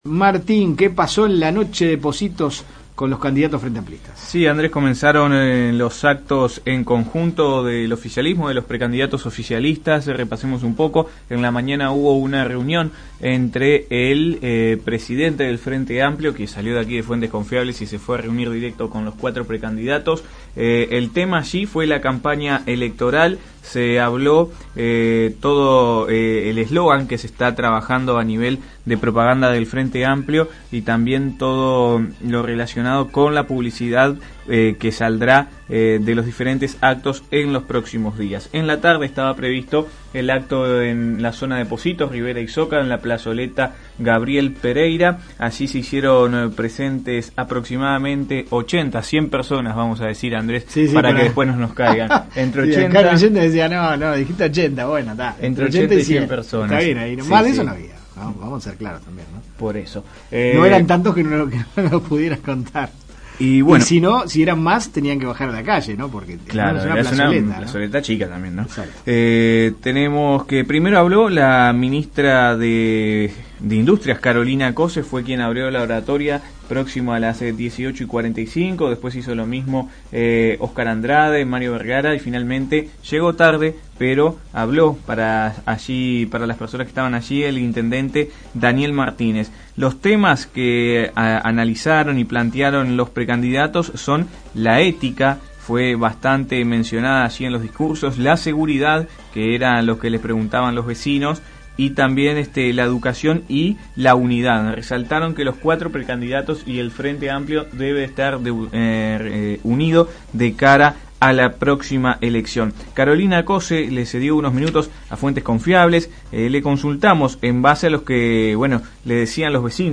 Se realizó el primer acto barrial de los cuatro precandidatos del Frente Amplio, Carolina Cosse, Óscar Andrade, Mario Bergara y Daniel Martínez que hablaron en la plazuela Gabriel A. Pereira en la esquina de Rivera y Soca en Pocitos sobre la situación de la seguridad, la economía, el empleo, la educación y la salud.